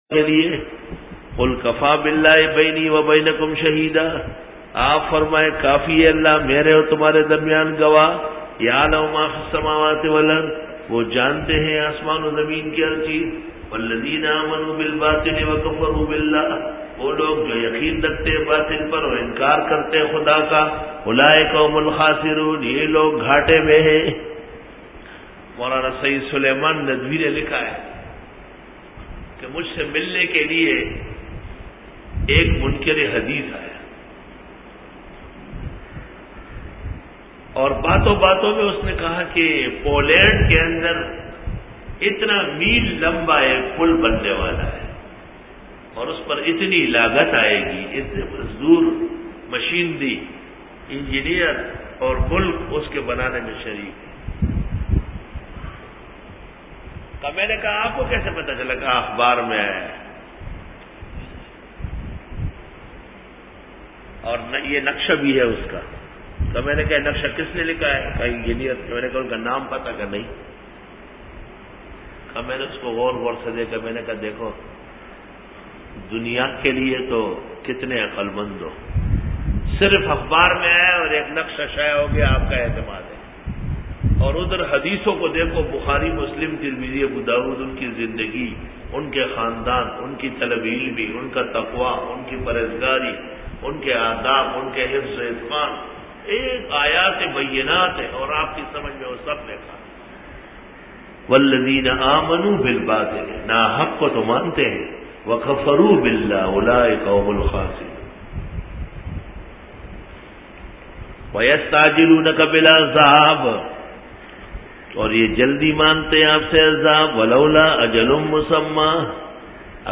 سورۃ العنکبوت رکوع-06 Bayan